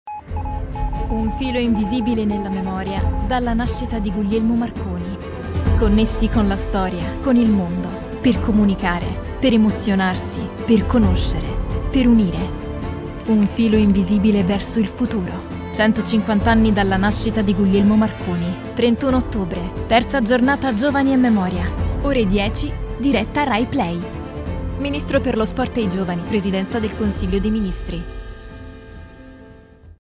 Female
Radio Commercials
Words that describe my voice are Engage, Natural, Energic.